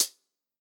UHH_ElectroHatA_Hit-26.wav